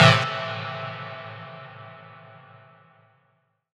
Piano Delay 2.wav